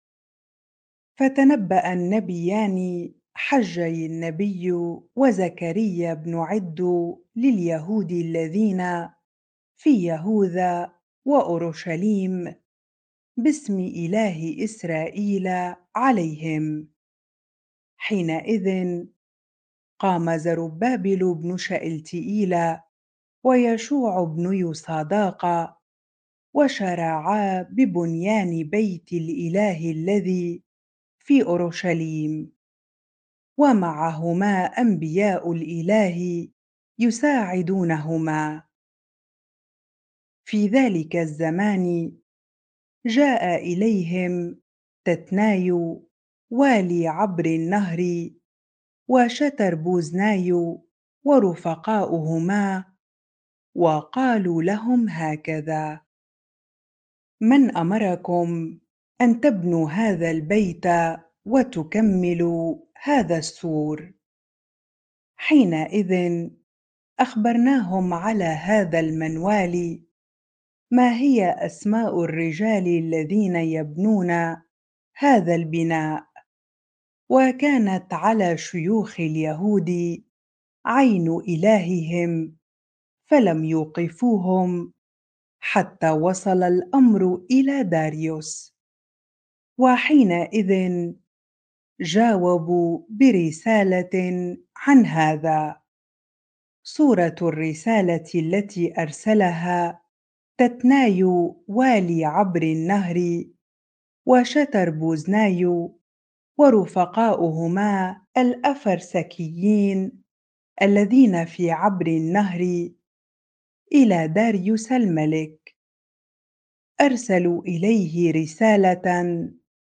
bible-reading-Ezra 5 ar